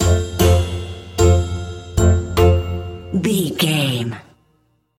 Ionian/Major
F#
Slow
orchestra
strings
flute
drums
goofy
comical
cheerful
perky
Light hearted
quirky